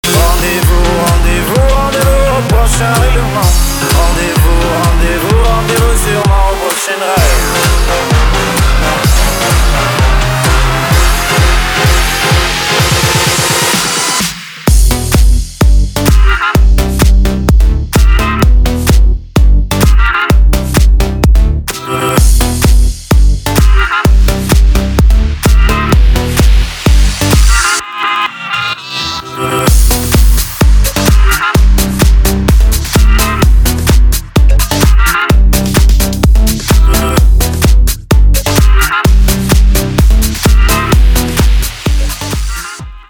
• Качество: 320, Stereo
Любим французский рэпчик, тогда ловите...